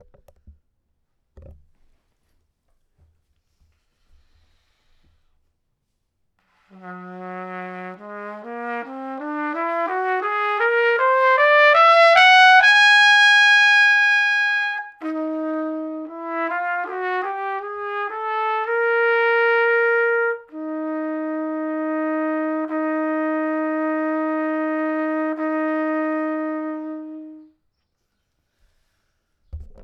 Trumpet Improv